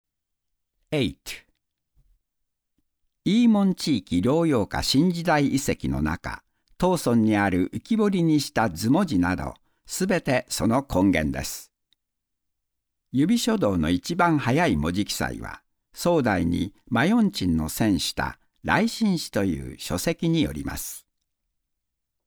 日语样音试听下载
Jp-male-DJ012-demo.mp3